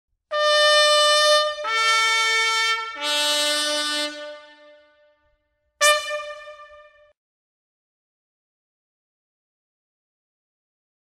Toques e Fanfarra das Tropas Pára-quedistas